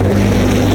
Machine ambient sounds
beam.ogg